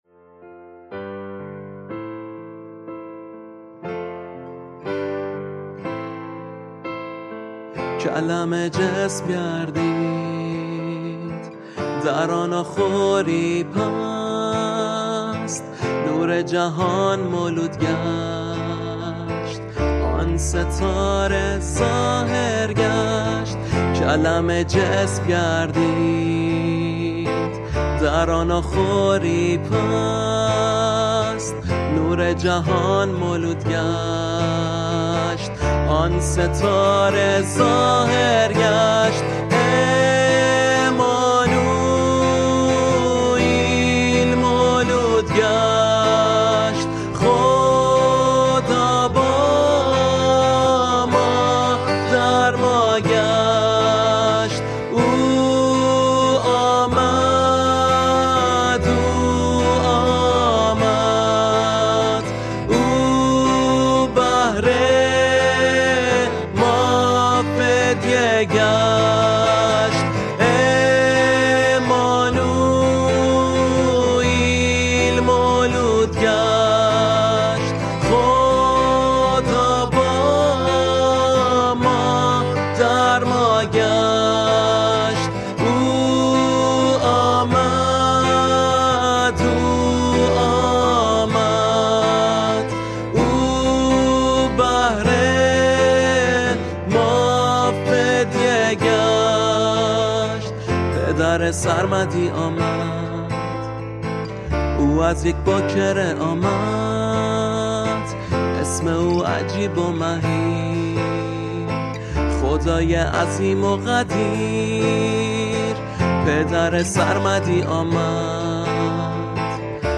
مینور